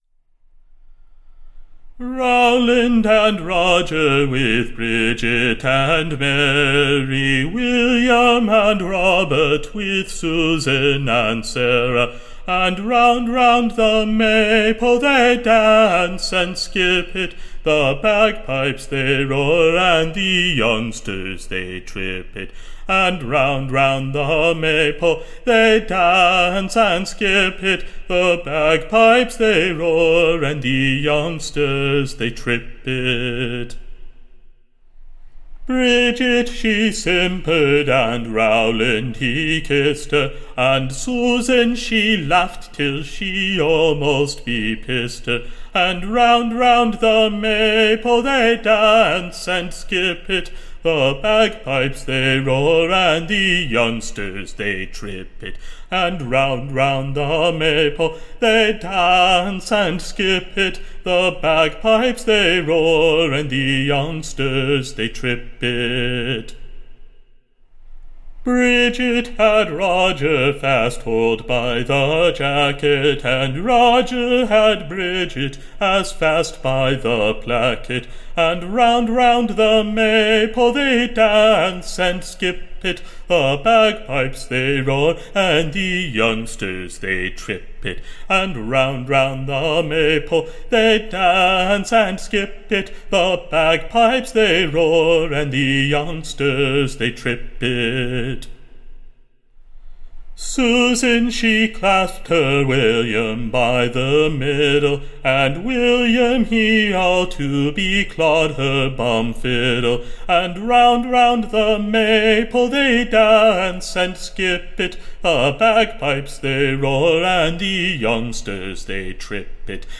Recording Information Ballad Title THE / Jovial May-pole Dancers: / OR, / The Merry Morris.